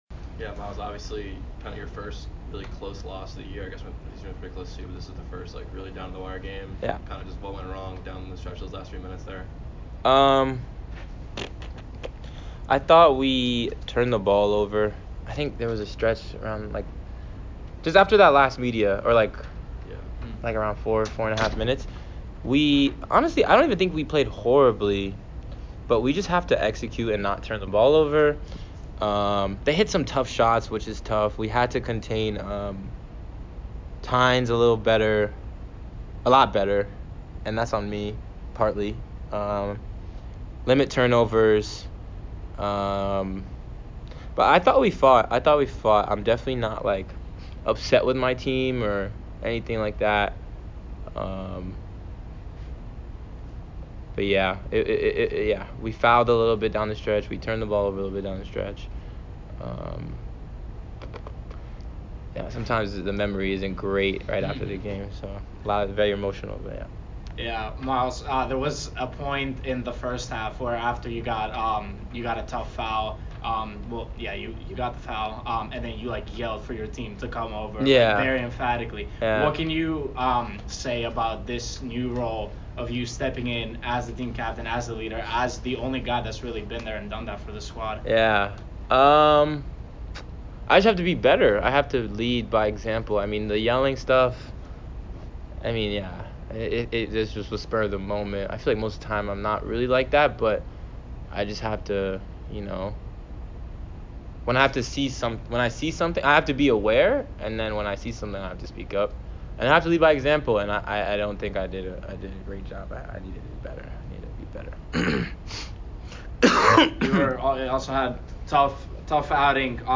Men's Basketball / Maine Postgame Interview (12-6-23)